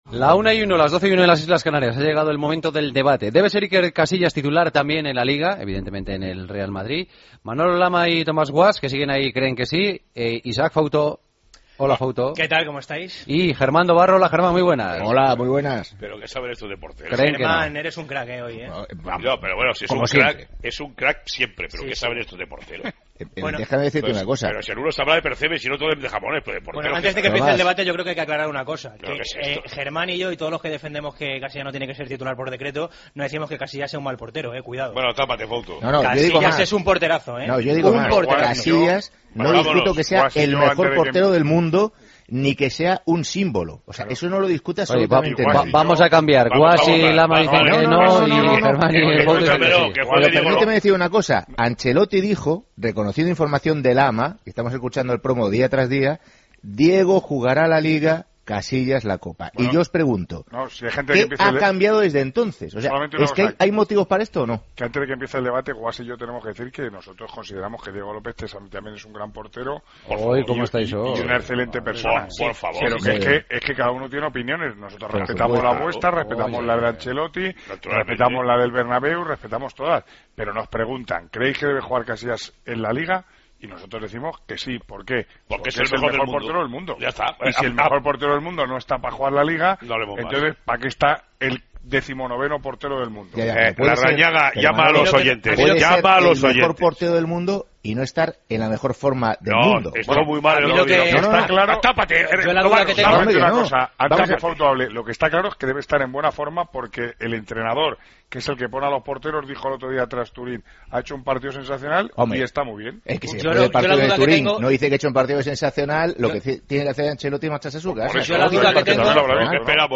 El debate de los jueves: ¿Debería ser Casillas también titular en Liga?